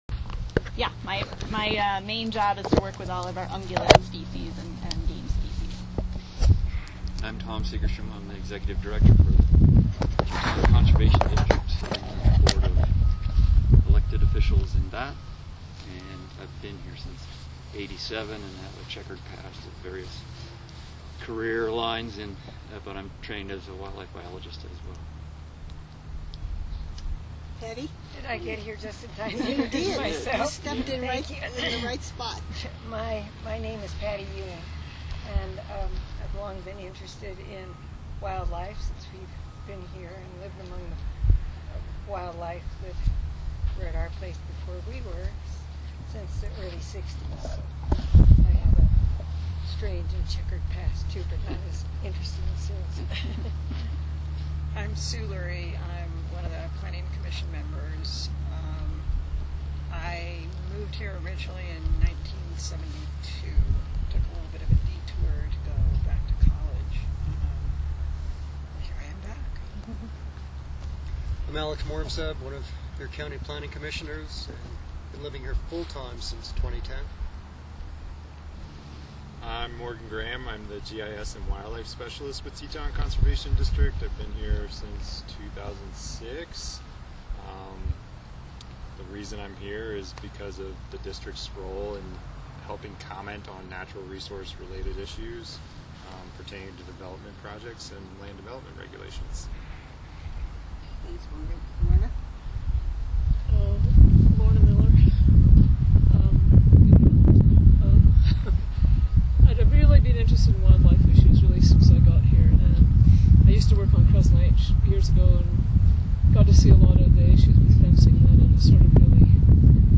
Planning Commissioners and Planning Staff conducted a site visit with Wyoming Department of Game and Fish to review examples of fencing in the community.